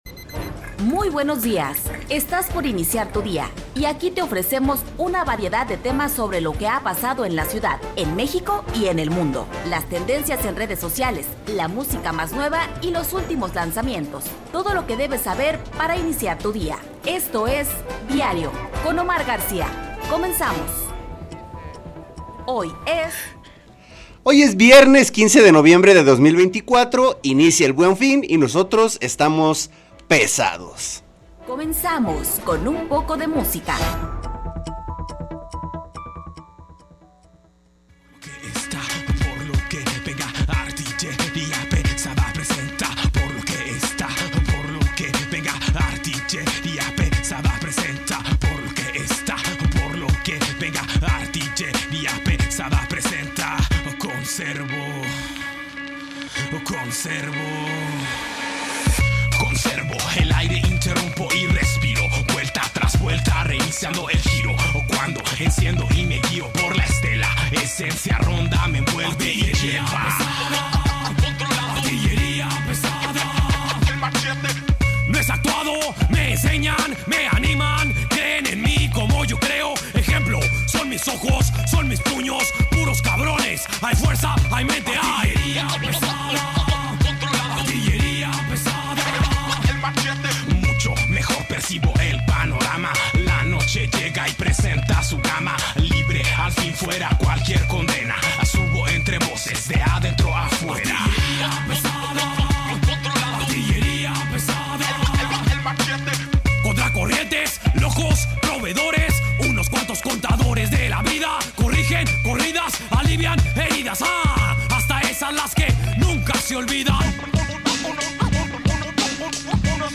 Revista Informativa de Radio Universidad de Guadalajara